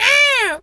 pgs/Assets/Audio/Animal_Impersonations/seagul_squawk_hurt_02.wav at master
seagul_squawk_hurt_02.wav